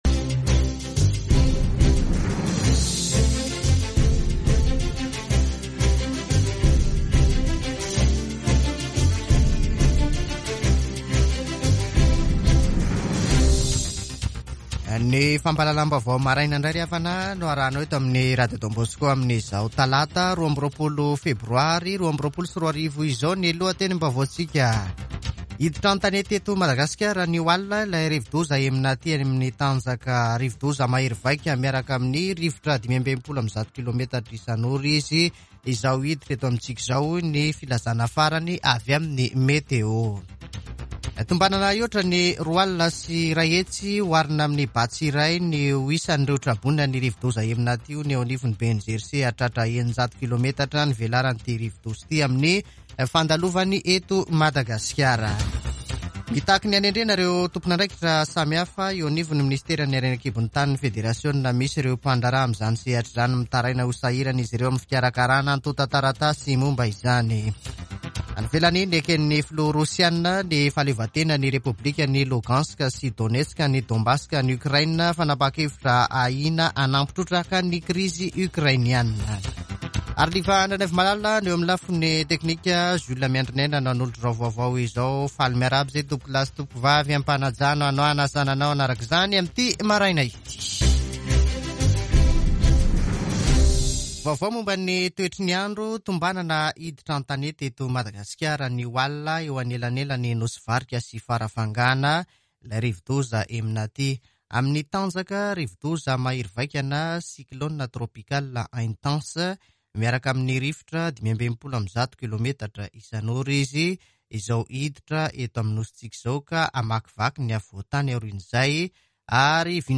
[Vaovao maraina] Talata 22 febroary 2022